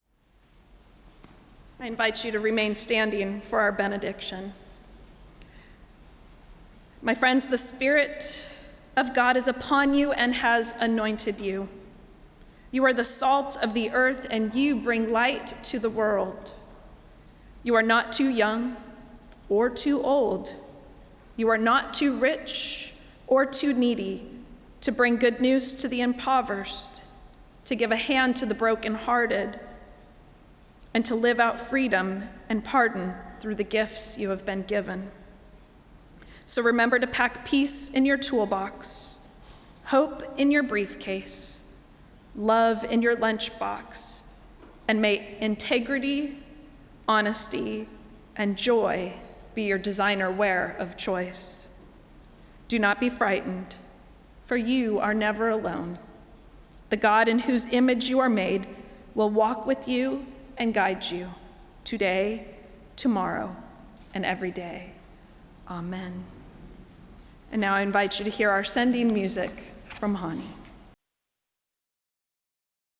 Service of Worship
Benediction